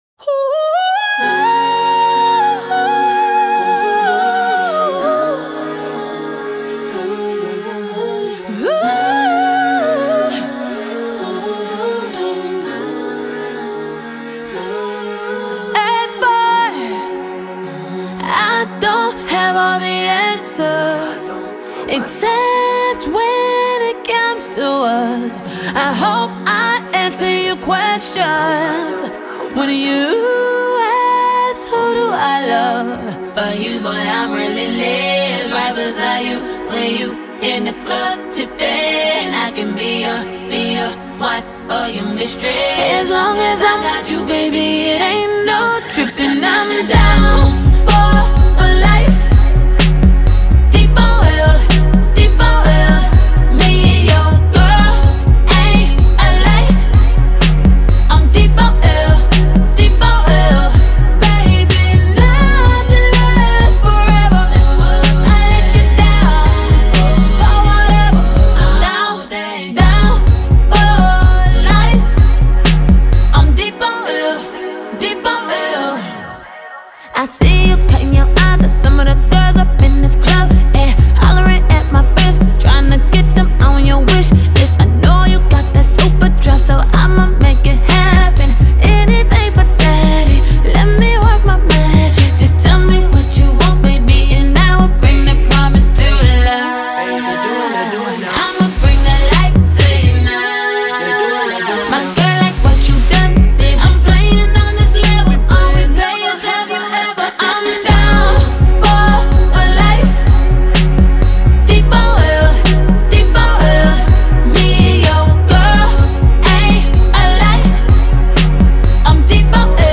The production is oddly minimalist and somewhat repetitive